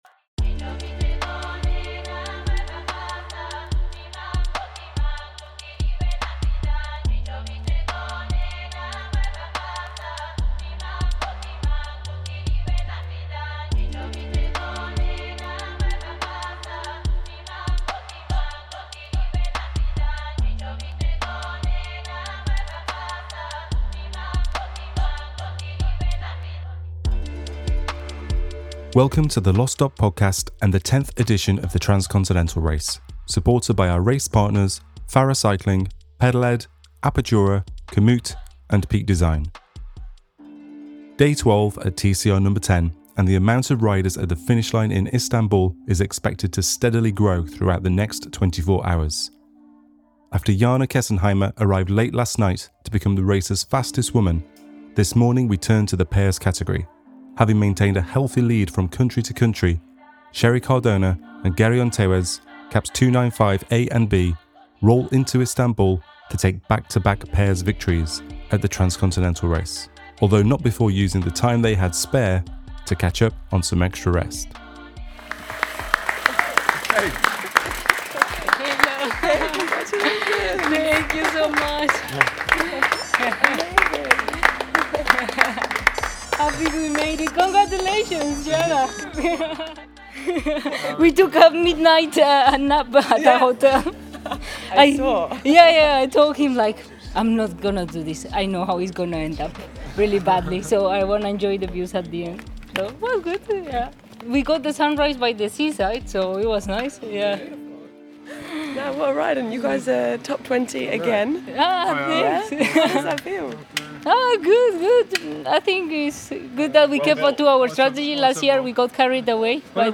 interviews riders as they embark on the final leg of their journeys through Türkiye.